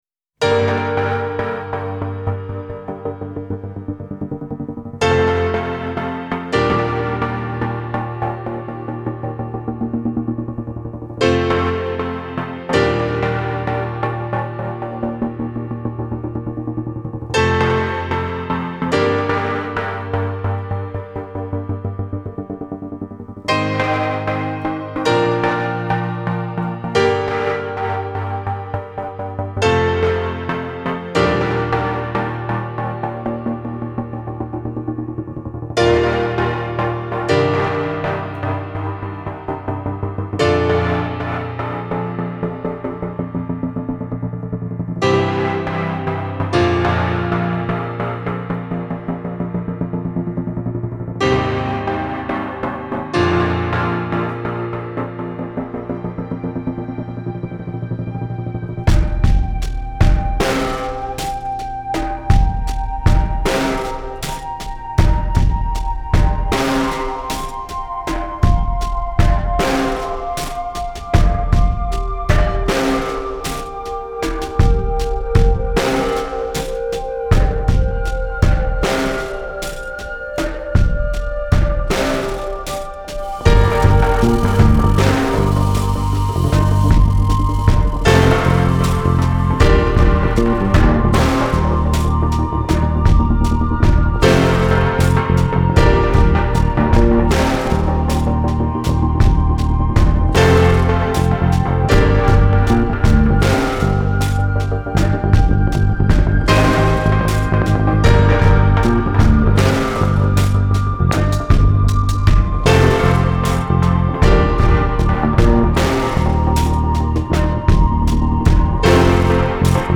Genre : Electronic